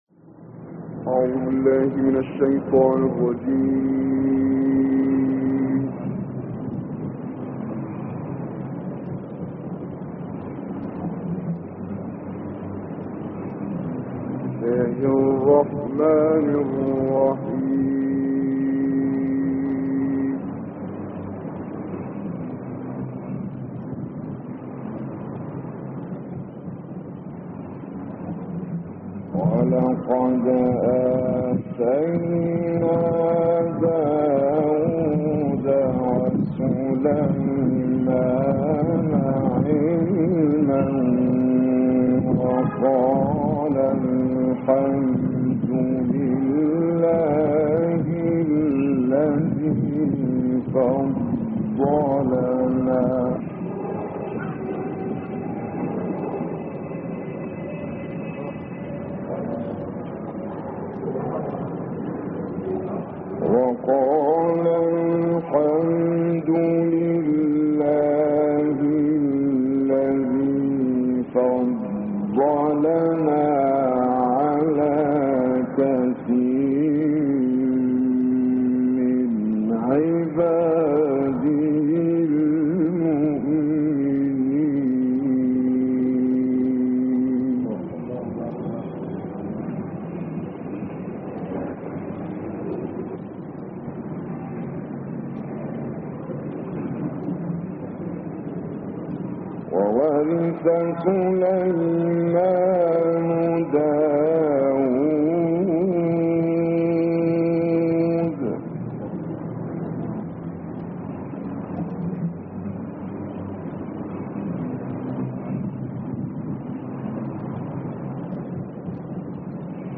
دانلود قرائت سوره نمل آیات 14 تا 44 - استاد کامل یوسف البهتیمی